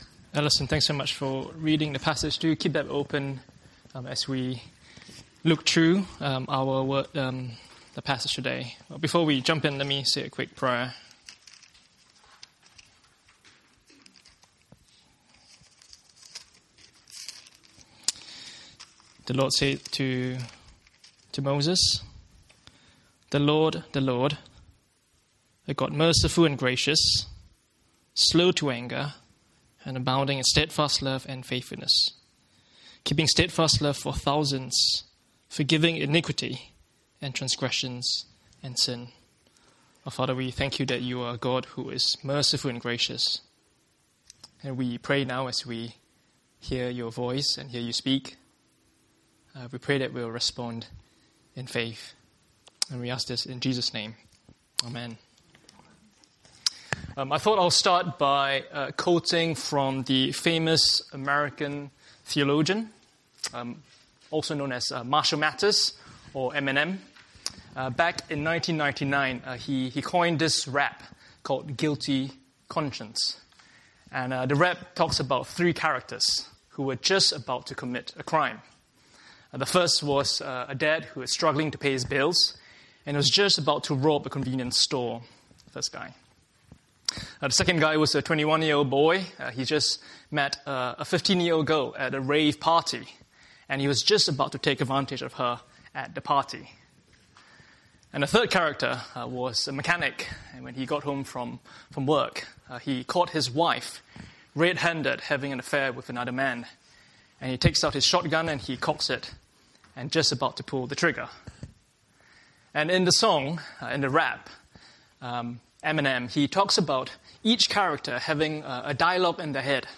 In this talk, we consider real help for troubled consciences.